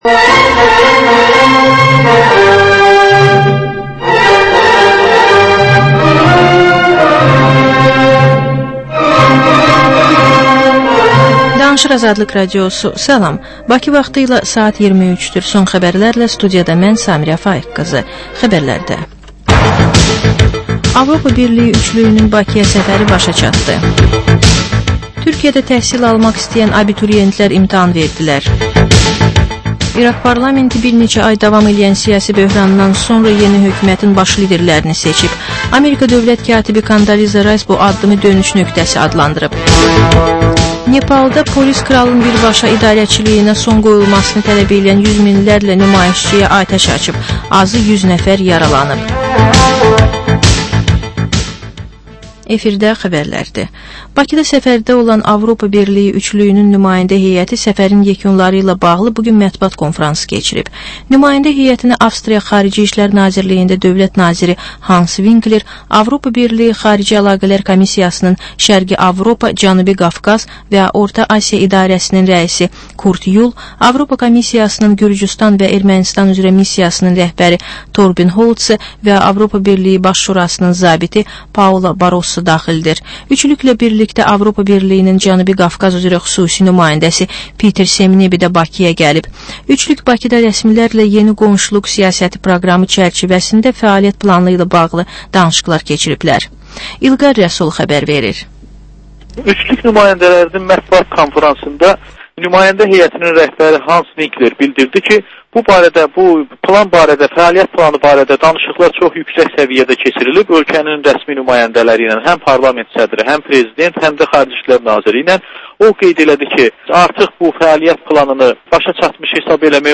Xəbərlər, reportajlar, müsahibələr